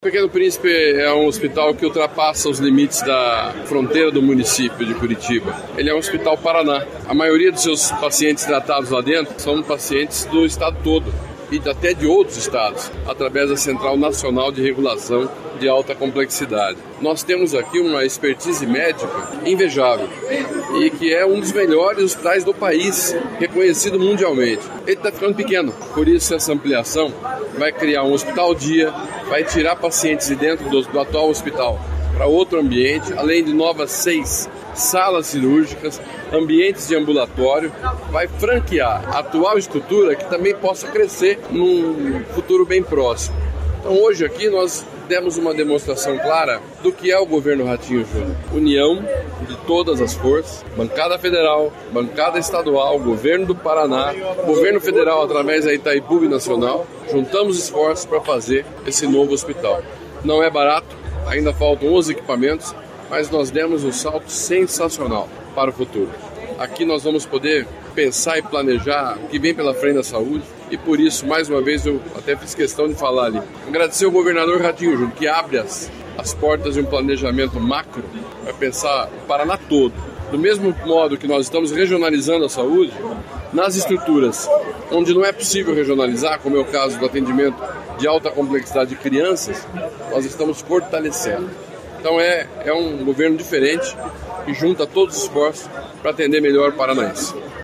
Sonora do secretário da Saúde, Beto Preto, sobre a construção do novo hospital do complexo Pequeno Príncipe